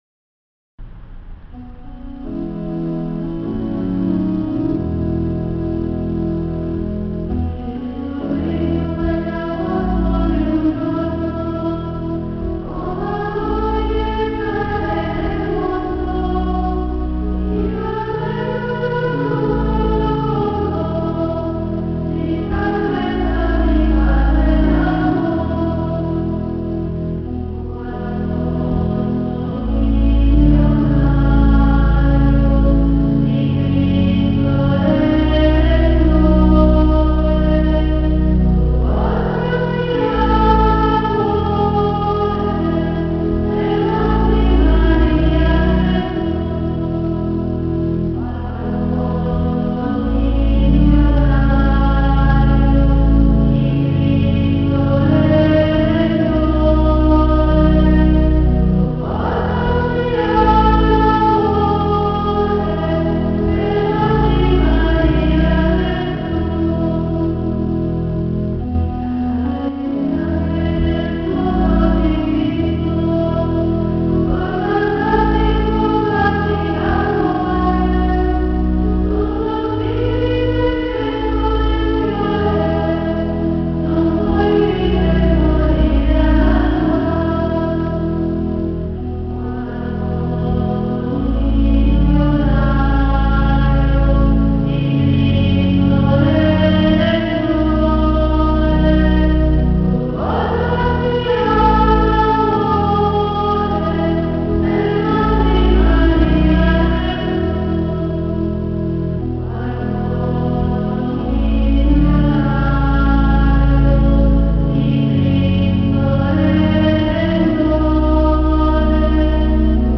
L’intero fascicolo è scaricabile in formato pdf; i testi dei singoli canti in formato *.txt. Anche le registrazioni in mp3 hanno valore di demo, cioè un aiuto ad imparare il canto. Le registrazioni sono state fatte qualche anno fa dalle Monache Redentoriste di Scala (purtroppo, non di tutti i canti).